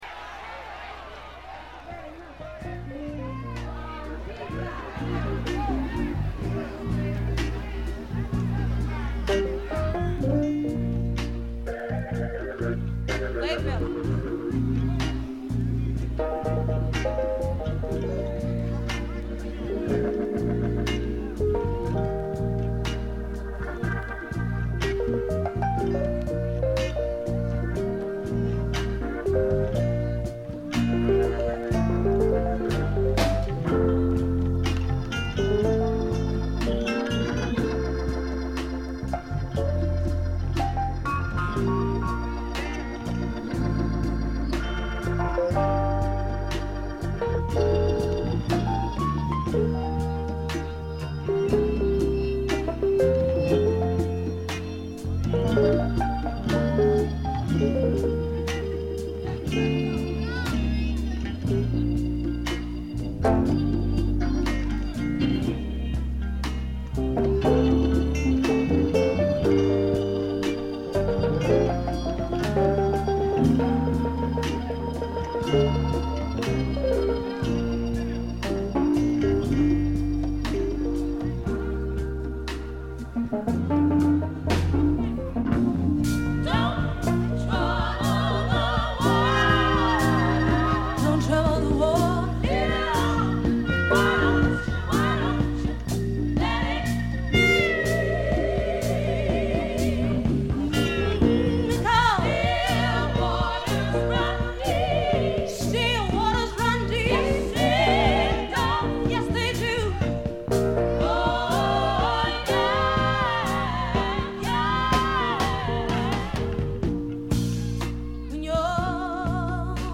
これ以外はほとんどノイズ感無し。
ソウル史上に燦然と輝く名作ライヴ。
試聴曲は現品からの取り込み音源です。